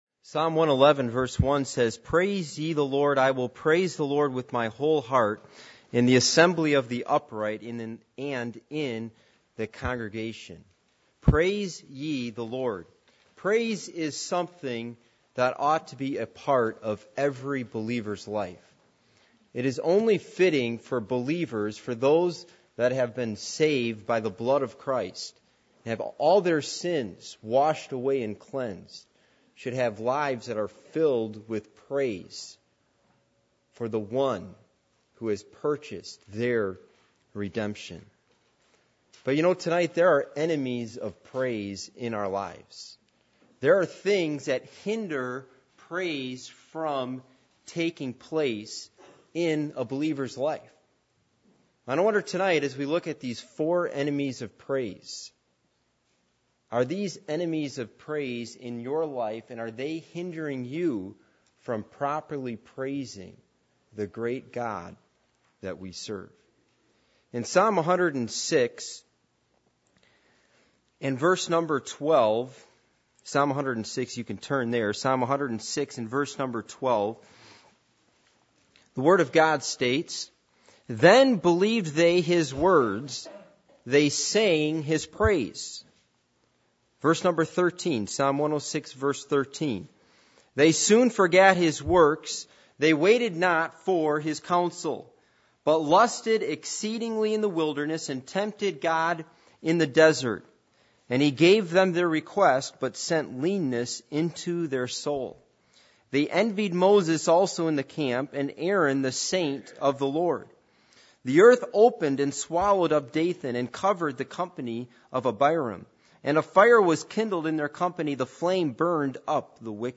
Passage: Psalm 106:12-22 Service Type: Midweek Meeting %todo_render% « Scoffers Of The Last Days